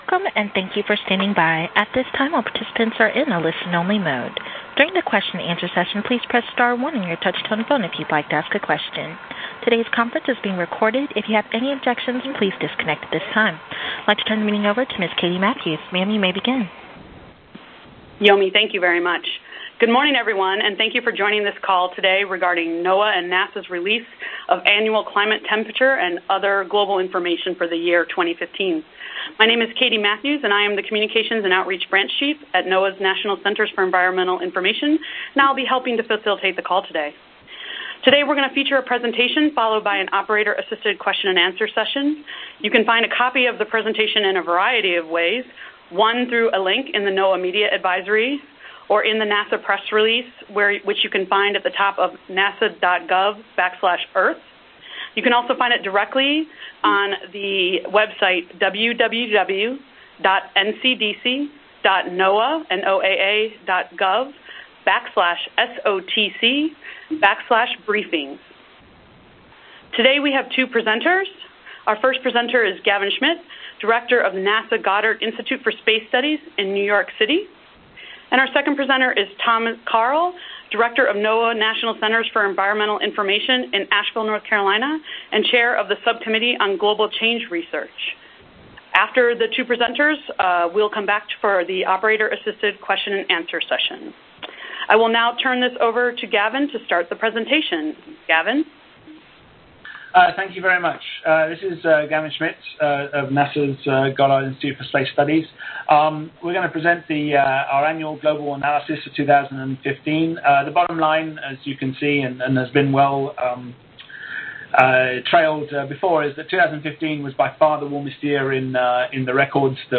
Climate experts from NOAA and NASA will announce new data on 2015 global temperatures during a media teleconference on Wednesday, Jan. 20, 11:00 a.m. ET. The scientists will also discuss the year's most important weather and climate events in context of long-term warming trends.